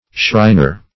shriner - definition of shriner - synonyms, pronunciation, spelling from Free Dictionary
Search Result for " shriner" : The Collaborative International Dictionary of English v.0.48: Shriner \Shrin"er\ (shr[imac]n"[~e]r), n. a member of the Ancient Arabic Order of Nobles of the Mystic Shrine.